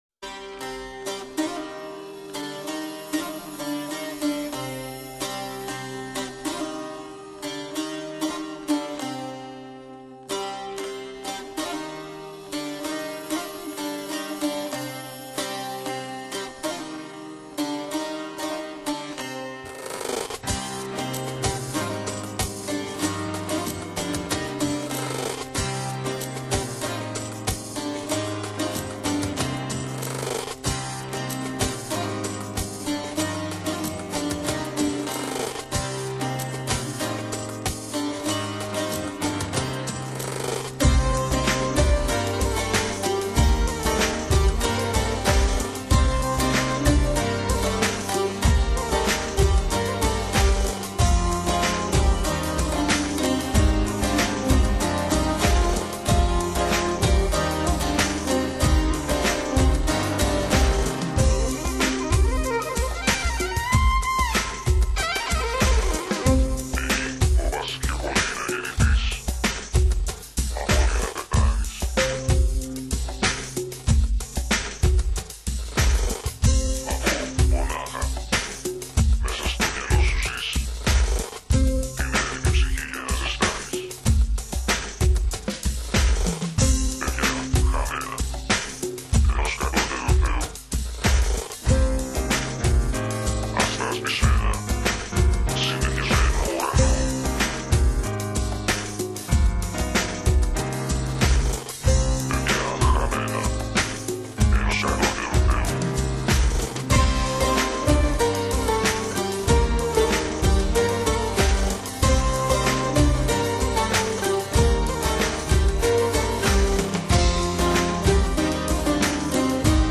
近几年希腊的音乐开始趋向于与世界音乐的融合